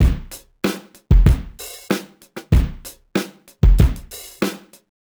Index of /musicradar/sampled-funk-soul-samples/95bpm/Beats
SSF_DrumsProc2_95-03.wav